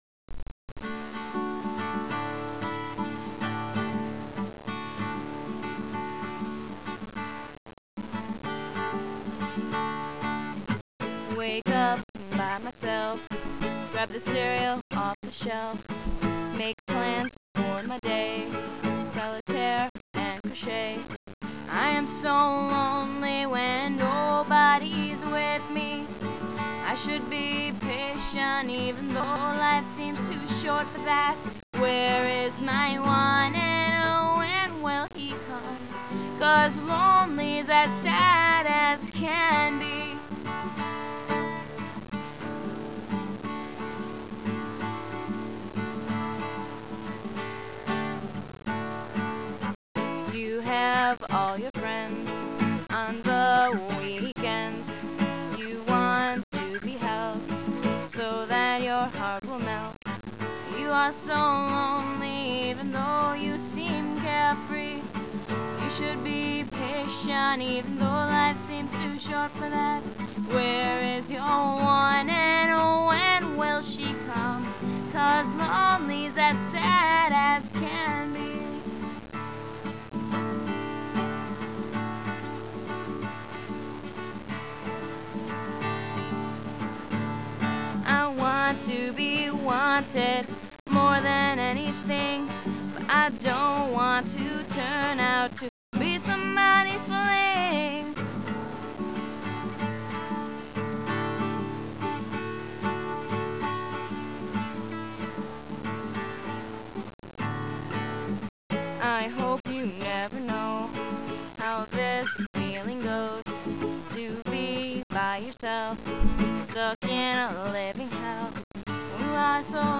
Hopefully updating this sometime soon (got the software and mic, just need to get off my butt and get it going) Once again sorry the sound quality is insanely bad.
This one is short, cause i was gonna put a cool guitar solo between the 3rd & 4th verse, but i'm bad at writing just guitar music (also I later realized it's kinda homophobic now that I think about it, but wasn't meant to offend!)